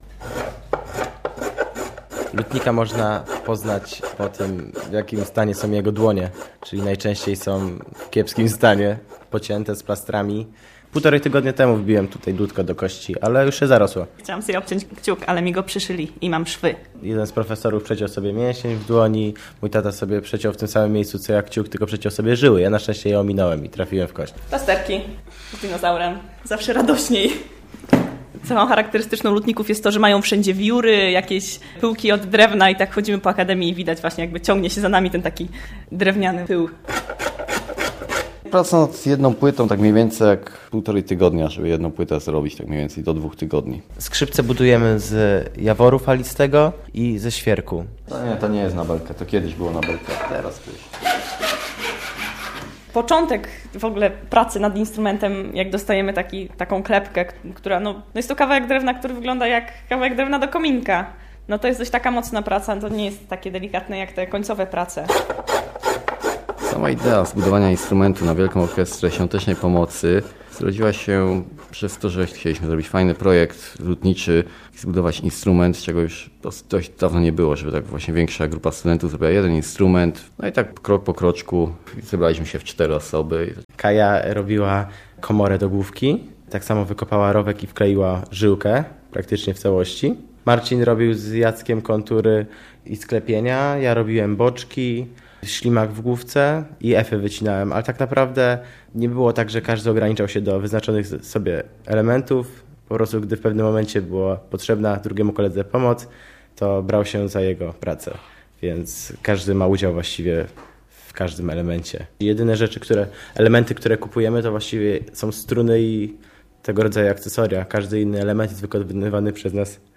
Drewniany pył - reportaż